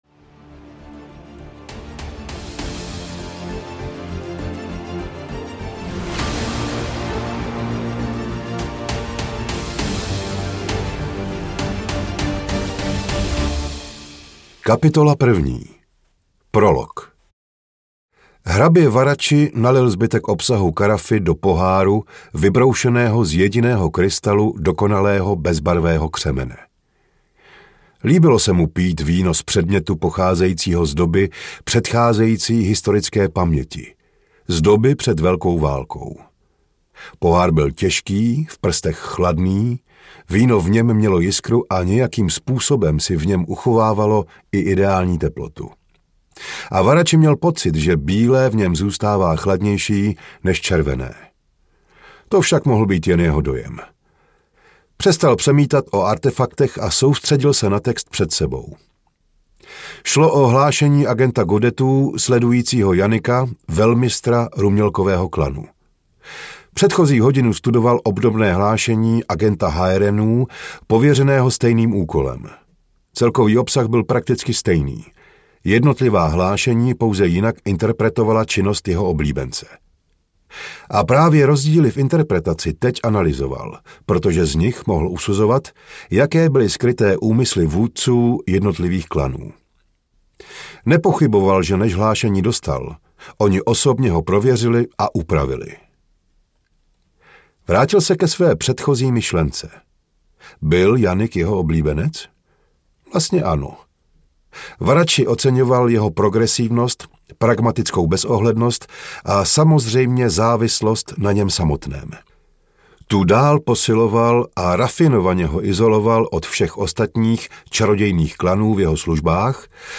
Bakly – Hledání smrti audiokniha
Ukázka z knihy
bakly-hledani-smrti-audiokniha